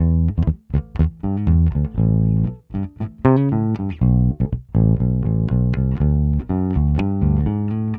Index of /90_sSampleCDs/Best Service ProSamples vol.48 - Disco Fever [AKAI] 1CD/Partition D/BASS-FINGER